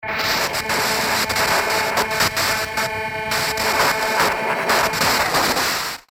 جلوه های صوتی
دانلود آهنگ رادیو 27 از افکت صوتی اشیاء